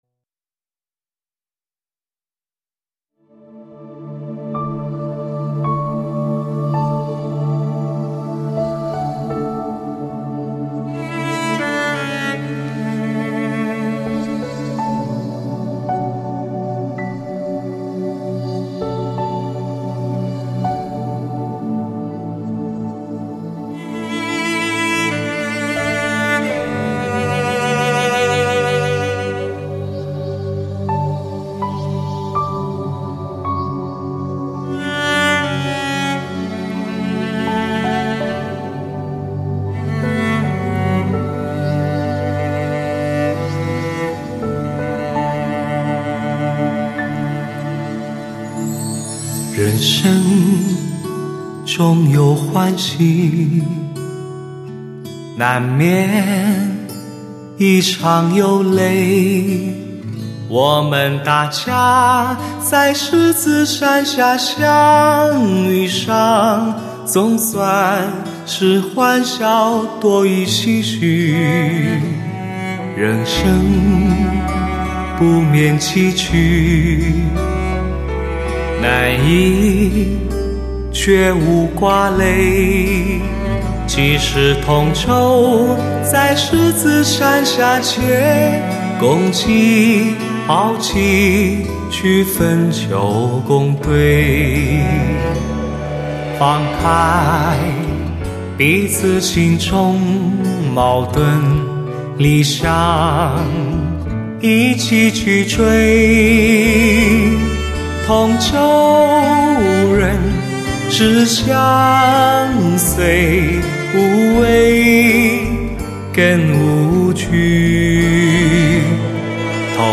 传奇声线与演唱艺术，成就发烧男声中的极品之作。包罗万象的忧伤、喜悦、热烈、奔放尽显其中。
本CD母盘采用K2HD编解码技术处理。